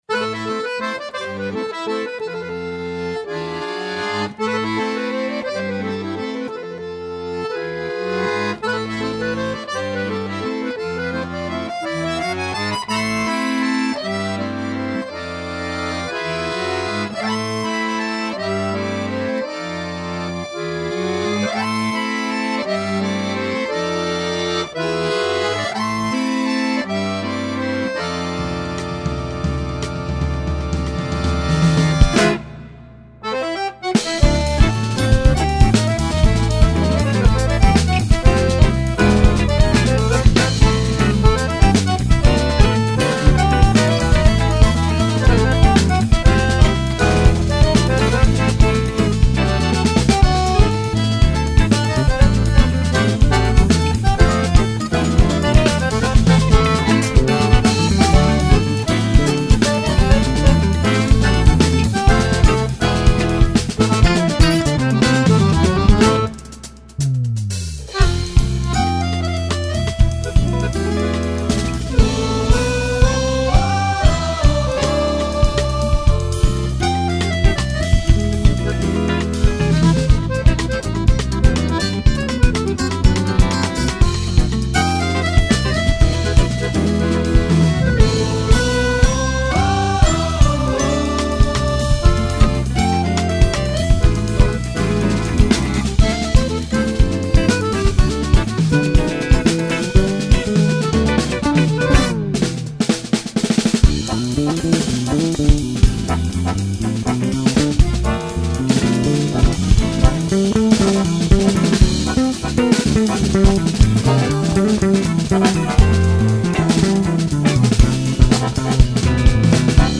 2805   04:12:00   Faixa: 3    Jazz
Bateria, Percussão
Baixo Elétrico 6, Violao Acústico 6
Guitarra, Viola
Acoordeon